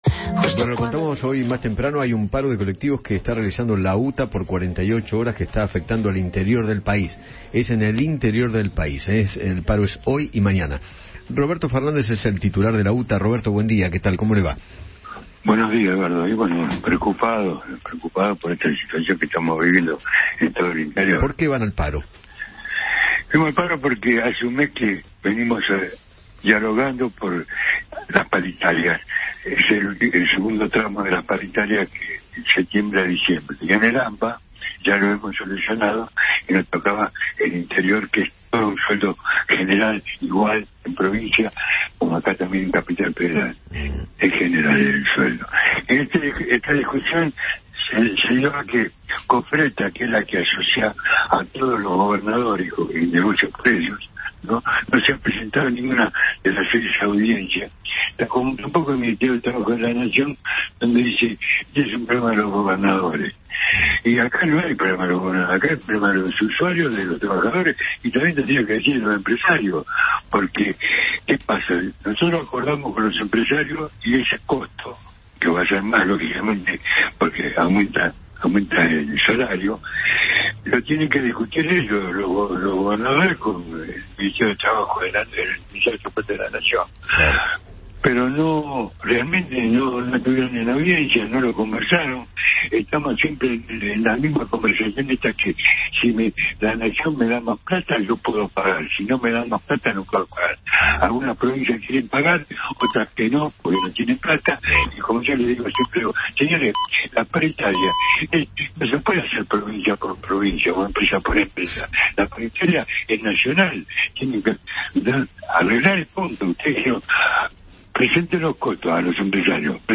Roberto Fernández, Secretario General de la UTA, conversó con Eduardo Feinmann sobre el paro de colectivos en el interior del país y el reclamo de los trabajadores.